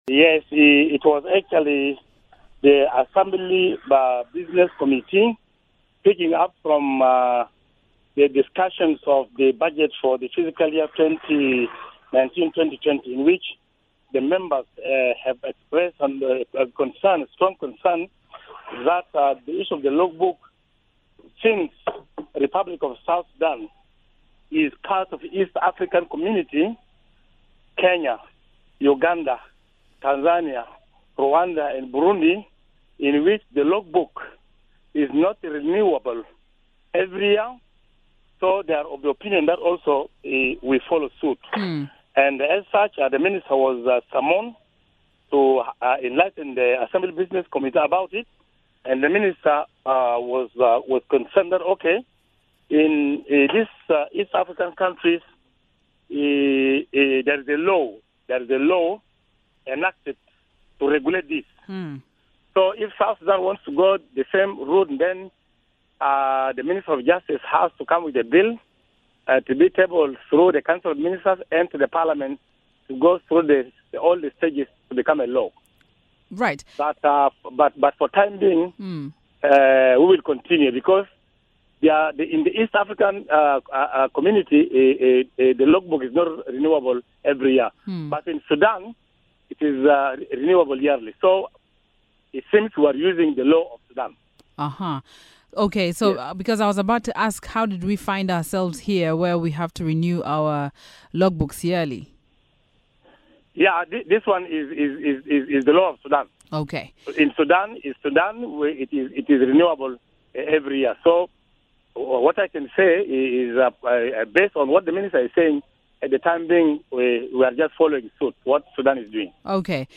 Miraya Breakfast spoke to the chairperson for specialised information committee in TNLA, Paul Yoane Bonju in the following interview.